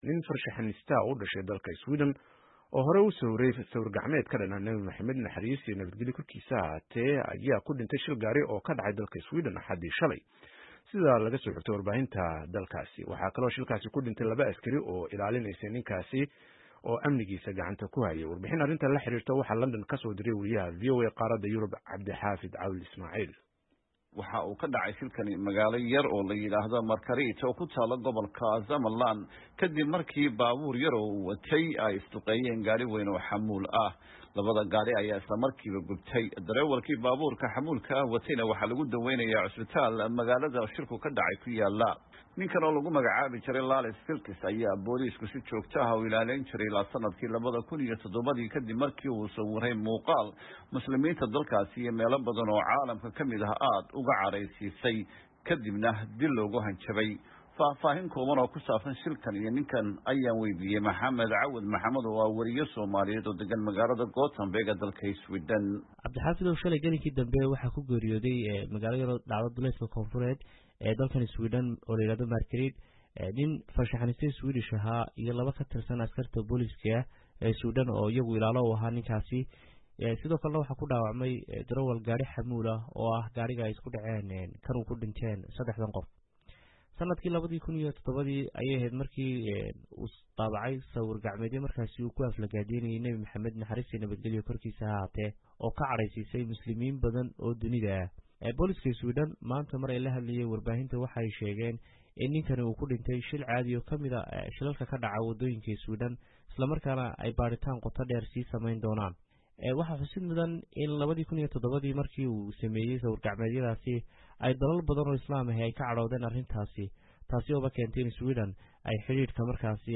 LONDON —